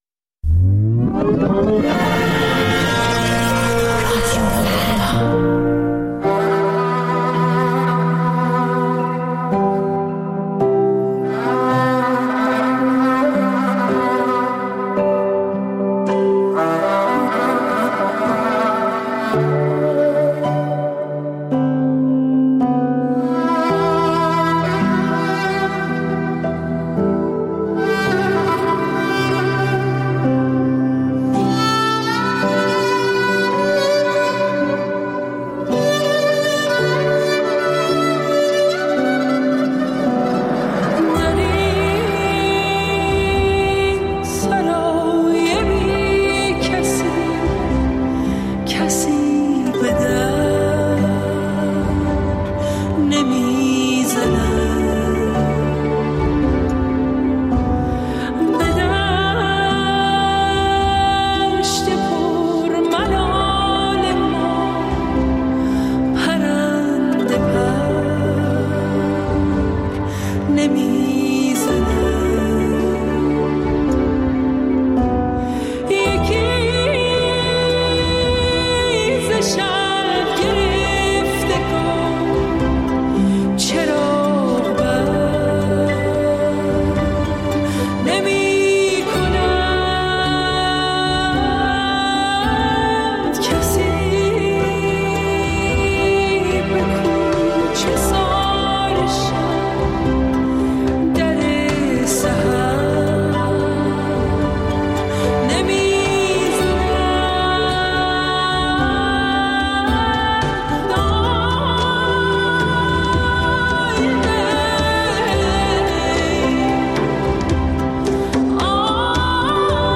دقایقی با موسیقی جز سول و بلوز